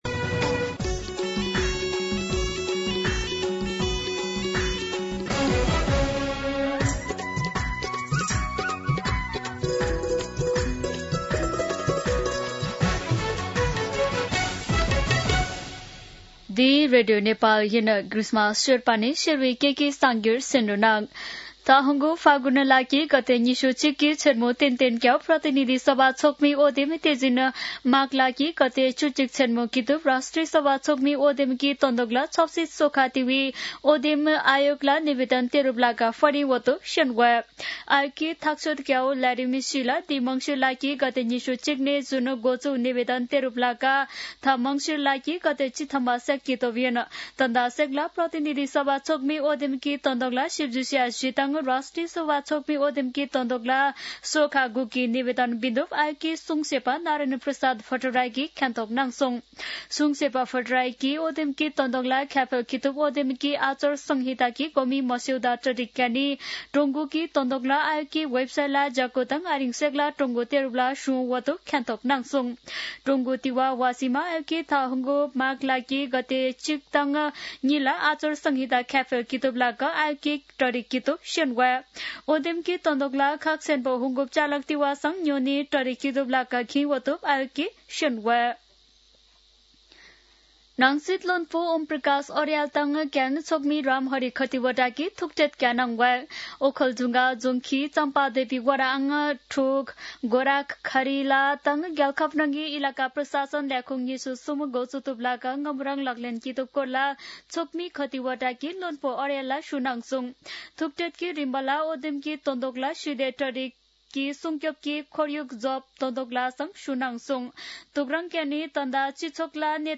शेर्पा भाषाको समाचार : ७ मंसिर , २०८२